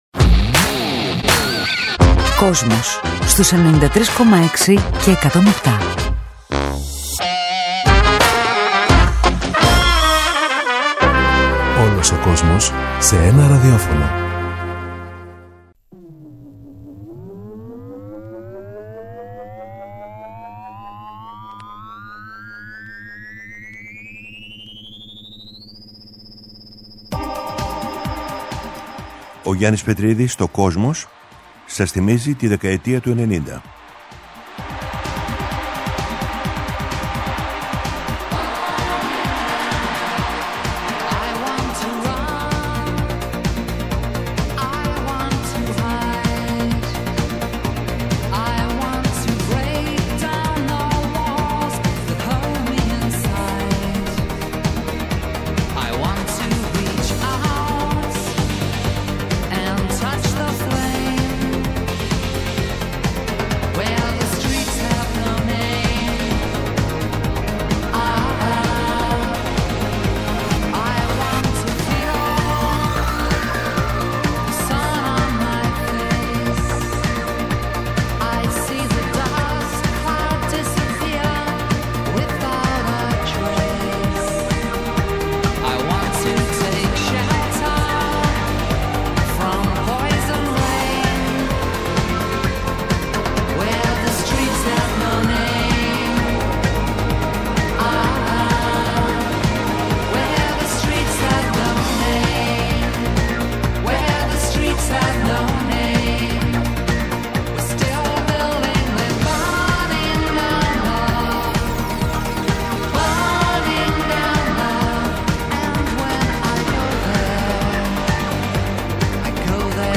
Μετά την ολοκλήρωση του αφιερώματος στη μουσική του 21ου αιώνα, ο Γιάννης Πετρίδης παρουσιάζει ένα νέο μουσικό αφιέρωμα στην 20ετία 1980-2000, από την Κυριακή 14 Μαρτίου 2021 και κάθε Κυριακή στις 19:00 στο Kosmos 93.6. Παρουσιάζονται, το ξεκίνημα της rap, η μεταμόρφωση του punk σε new wave, οι νεορομαντικοί μουσικοί στην Αγγλία, καθώς και οι γυναίκες της pop στην Αμερική που άλλαξαν τη δισκογραφία.